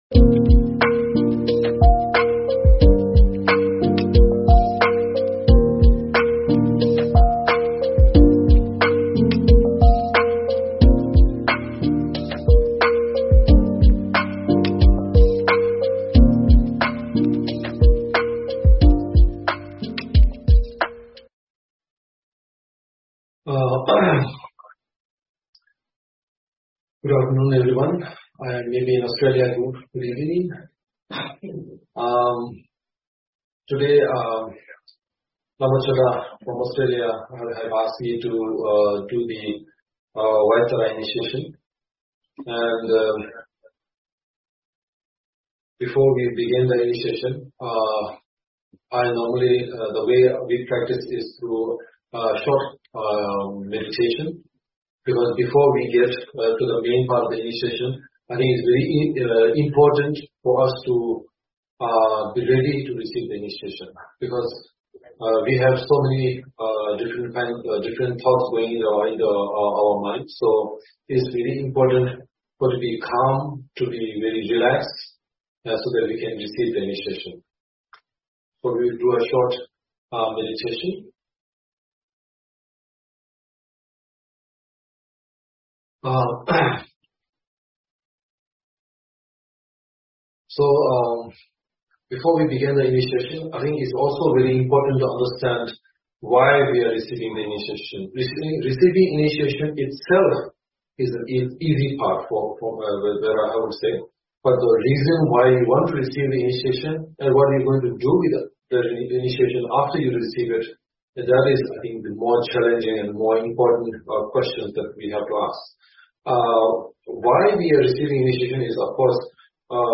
Topic: Advice Before and After a White Tārā Initiation: On Self-Knowledge, Balance, and Acceptance Author: H.H. the 43rd Sakya Trizin Venue: Online Video and Audio Source: Sakya Friends Youtube
Belongs to This Album Teaching Given in 2023 H.H. the 43nd Sakya Trizin's Dharma Teaching Given in 2023